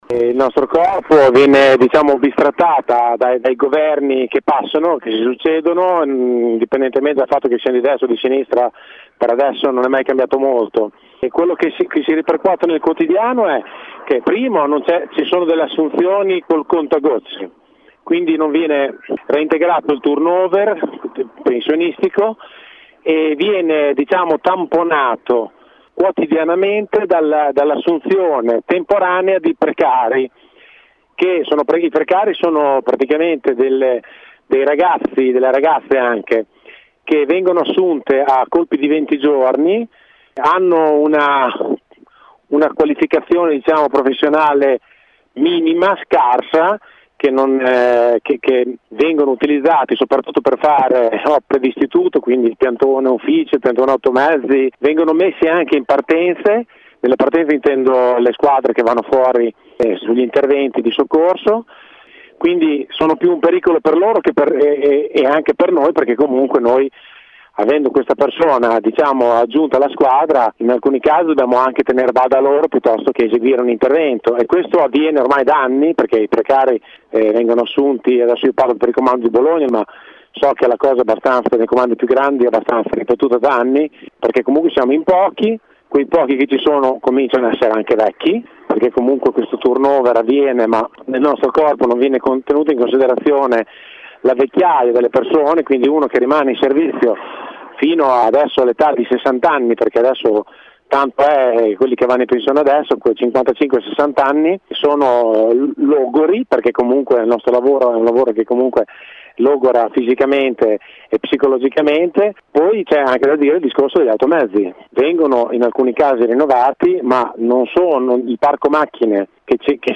sentiamo un vigile del fuoco a Bologna